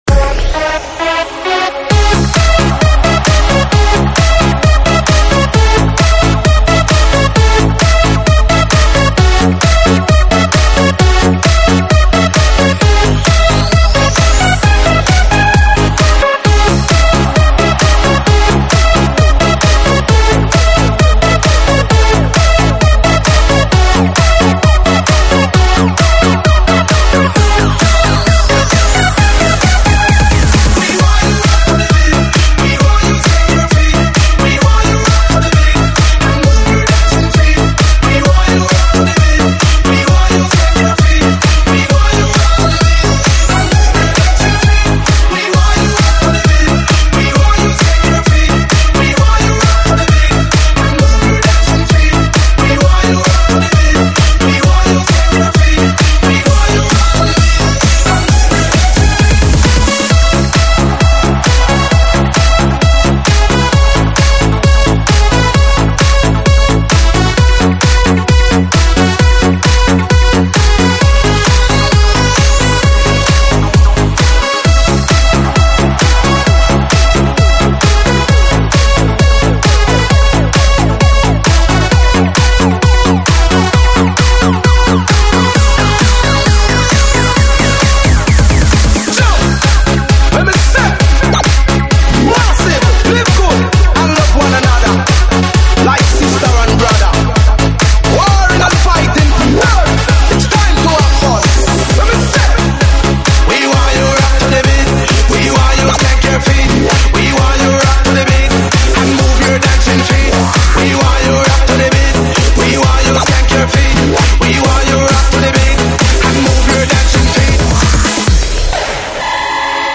舞曲类别：现场串烧